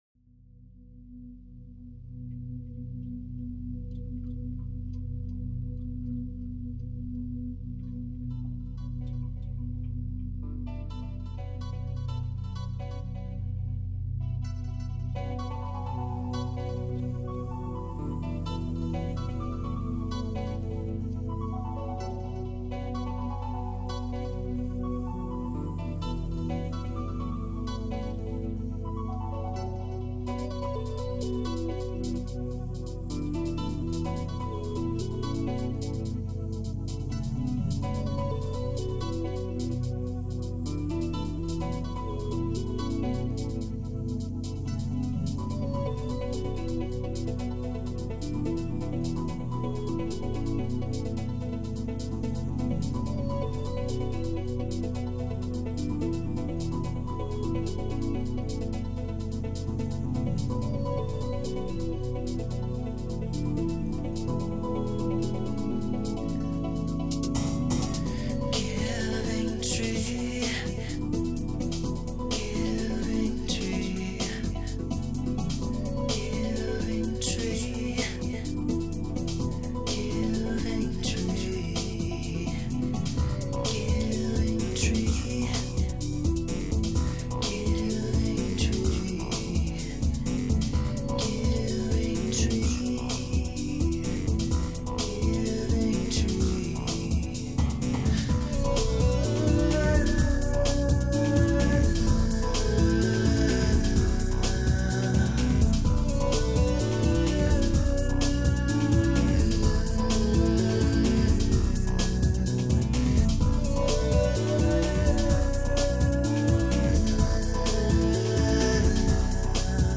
ambient to light techno sound